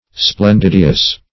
Splendidious \Splen*did"i*ous\